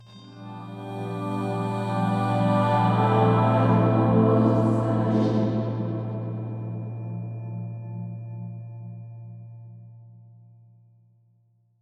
choir c
ambience choir choral church cinematic music pad processed sound effect free sound royalty free Music